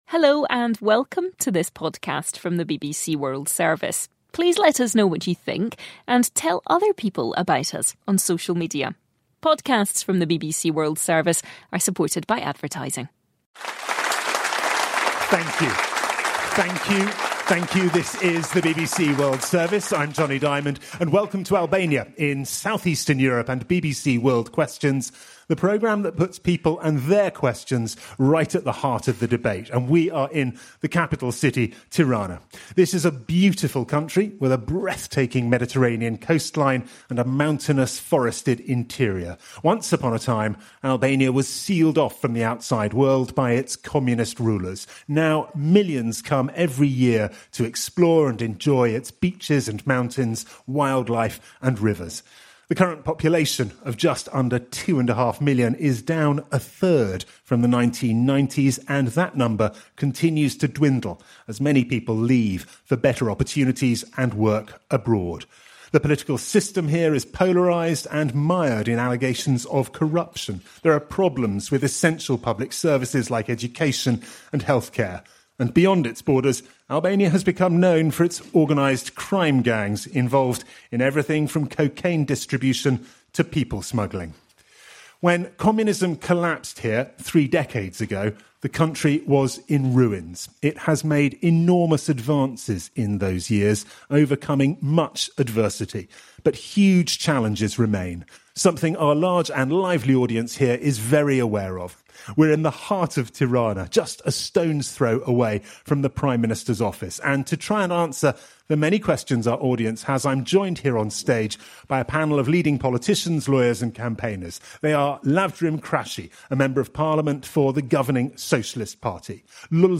BBC world Questions, një program i BBC ishte të martën e shkuar në Tiranë për të folur për problemet e Shqipërisë